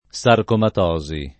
sarcomatosi [ S arkomat 0@ i ] s. f. (med.)